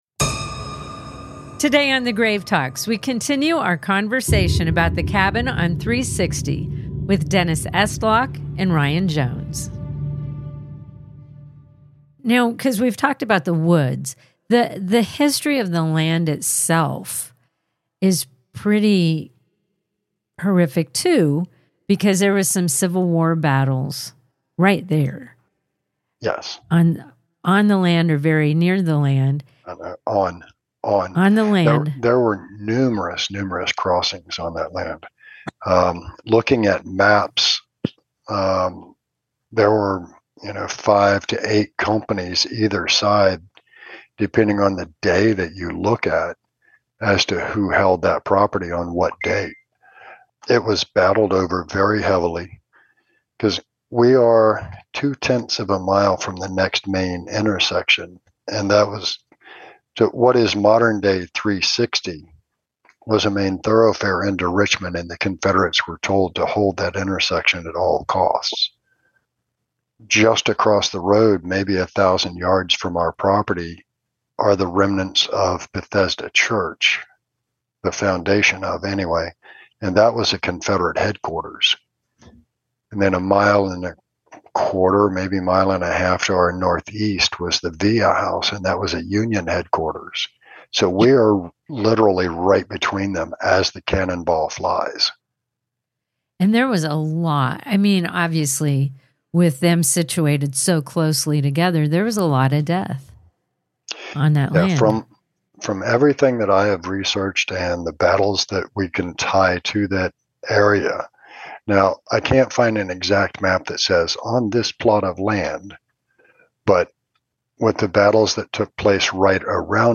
Part Two of our conversation about The Cabin on 360